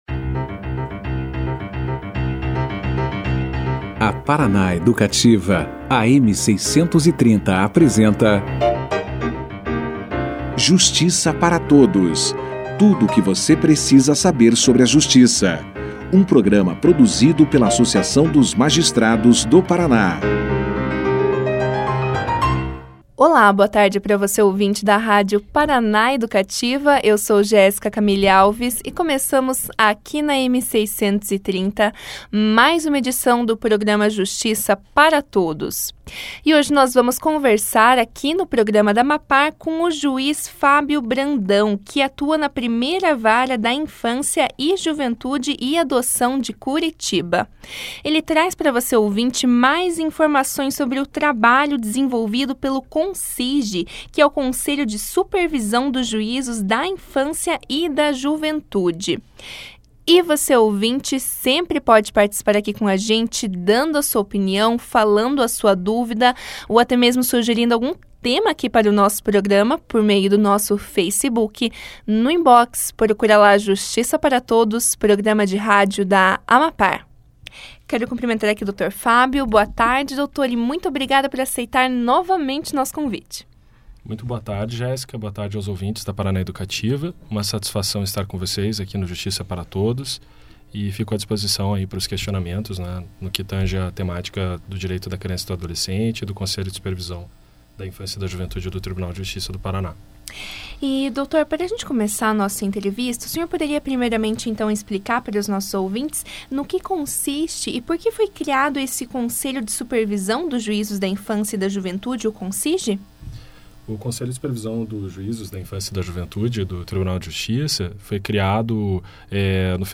O juiz Fábio Brandão, que atua na 1ª Vara da Infância e Juventude e Adoção de Curitiba, conversou com o Justiça para Todos, na sexta-feira (23) sobre o trabalho desenvolvido pelo Conselho de Supervisão dos Juízos da Infância e da Juventude do TJPR, o CONSIJ. O magistrado deu início a conversa explicando os fatores que motivaram a criação de um conselho nesta área e destacou as principais frentes de trabalho e as maiores conquistas do CONSIJ.